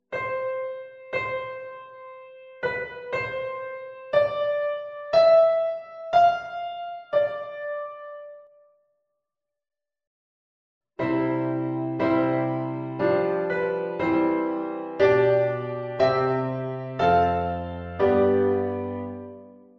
Harmonie houdt zich bezig met het verbinden van (in de regel: vierstemmige) akkoorden.
uitwerking van een gegeven sopraan